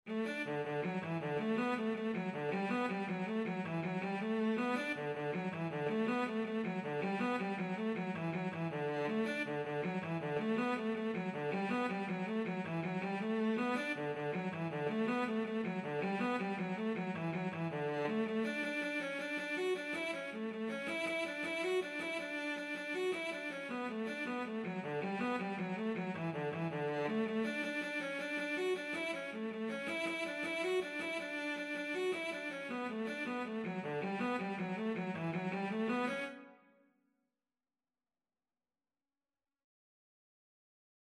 Free Sheet music for Cello
Traditional Music of unknown author.
6/8 (View more 6/8 Music)
D major (Sounding Pitch) (View more D major Music for Cello )
Cello  (View more Easy Cello Music)
Traditional (View more Traditional Cello Music)
Irish